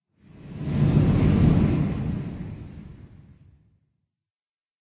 cave11.ogg